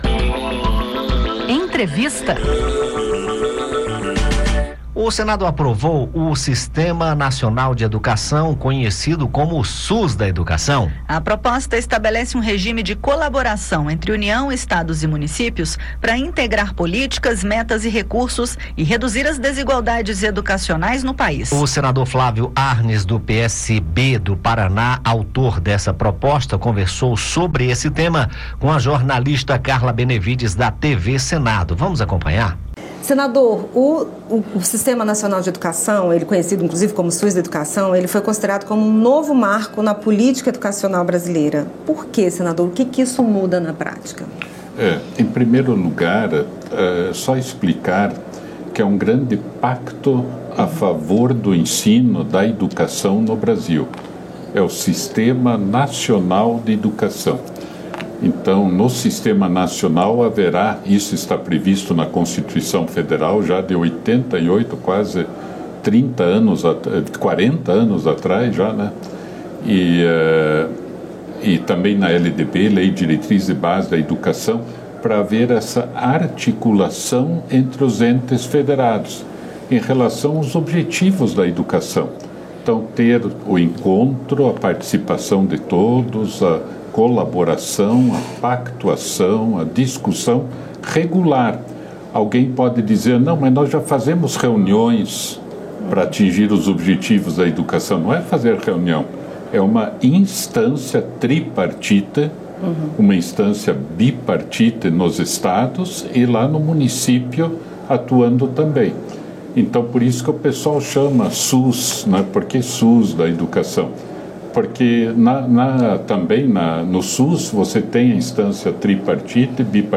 Projeto que institui o Sistema Nacional de Educação foi aprovado pelo Senado no início de outubro e enviado à sanção. O sistema estabelece um modelo de colaboração entre os entes federativos (União, estados e municípios) para integrar políticas públicas, recursos e metas da educação e reduzir desigualdades regionais. Em entrevista